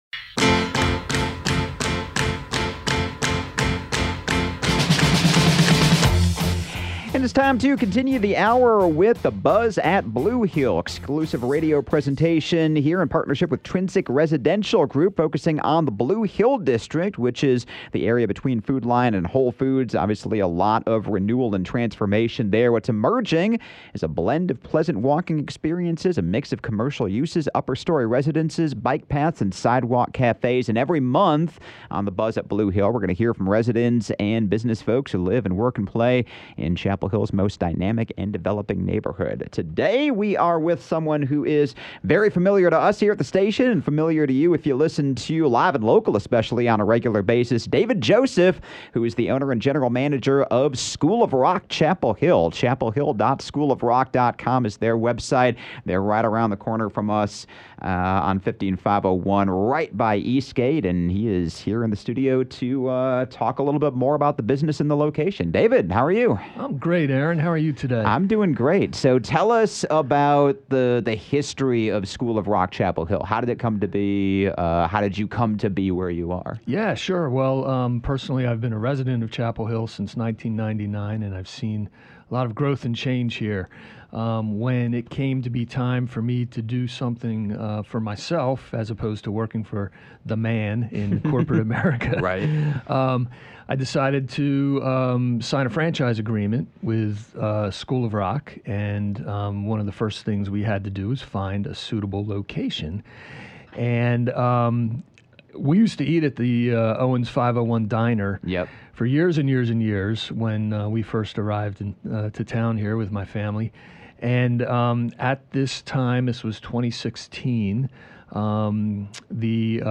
“The Buzz at Blue Hill” is an exclusive radio presentation in partnership with Trinsic Residential Group on 97.9 The Hill.
As the Blue Hill District continues to undergo significant renewal and transformation, from walking paths and bike paths running past residences to sidewalk cafés and commercial properties, check back here each month for a new interview!